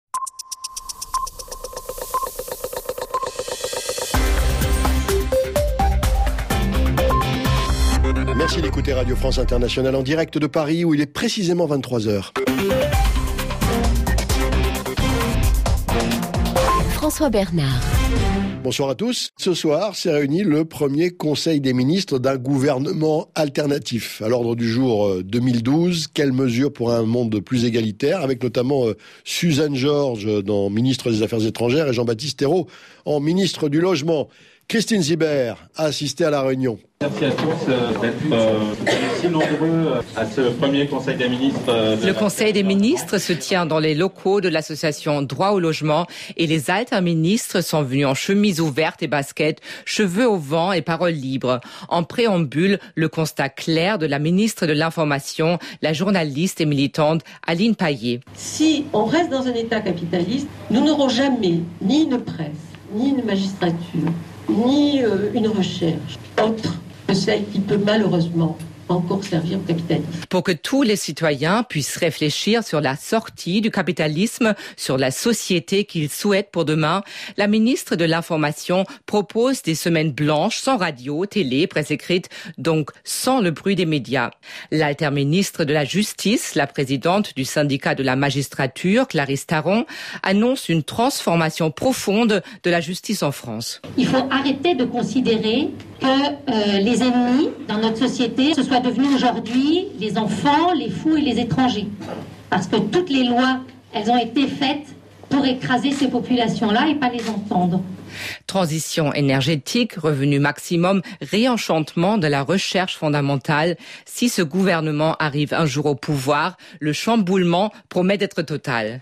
Elle en rend compte dans le journal de 23 heures sur les ondes de RFI.